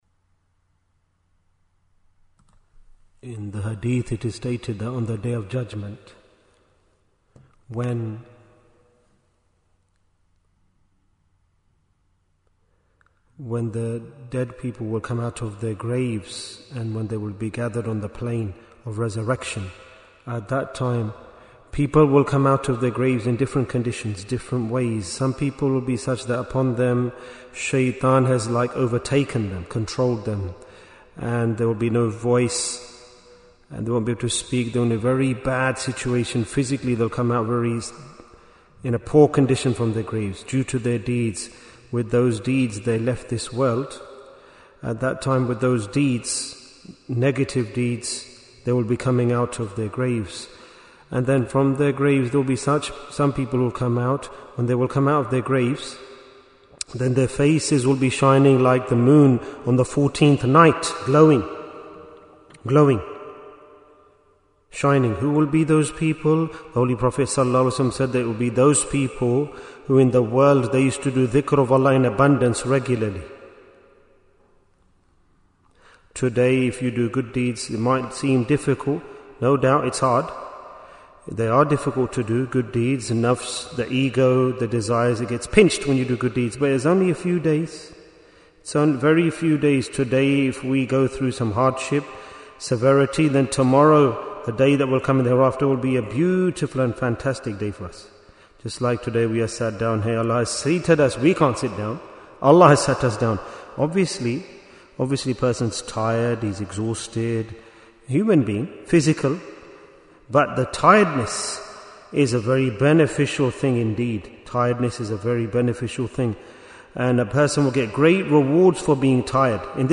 Bayan, 9 minutes